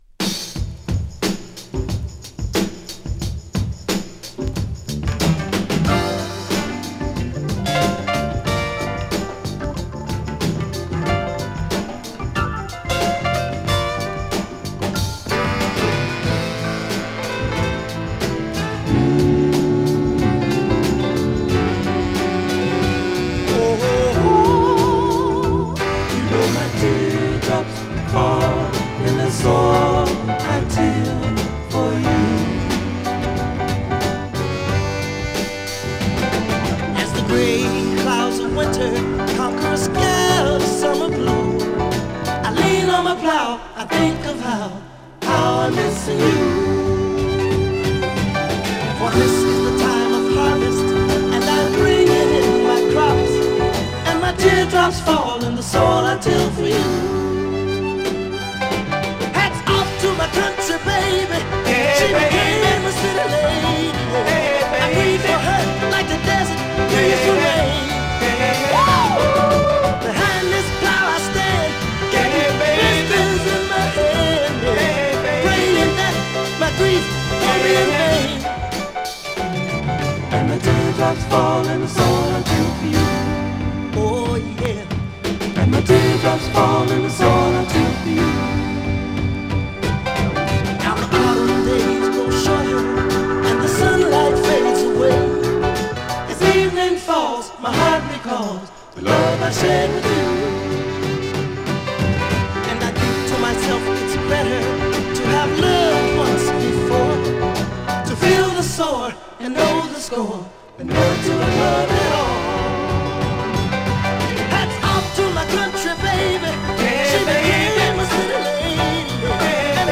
どの曲も乾いたサウンドの切れ味バツグンなドラムブレイクばかりを収録。